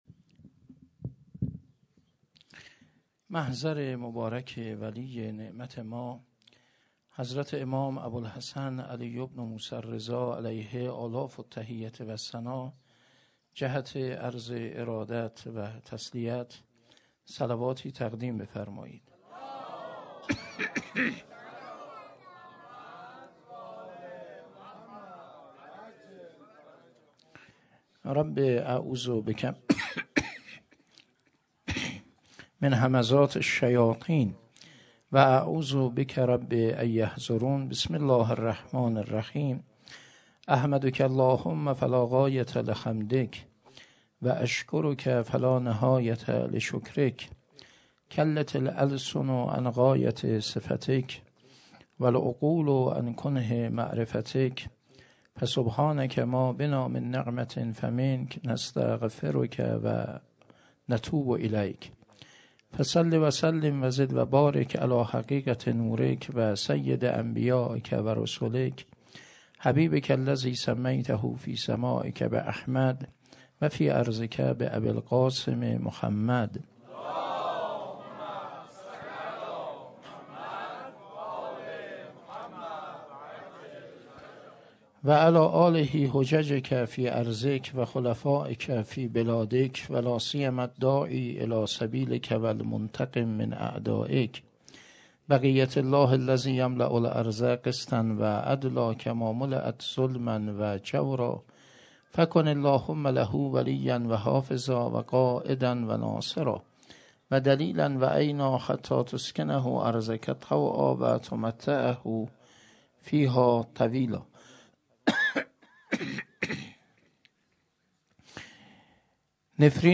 بیست و ششم محرم 96 - سفره اشک - سخنرانی